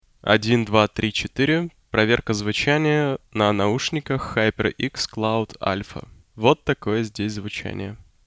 Можете сравнить с микрофоном HyperX Cloud Аlpha: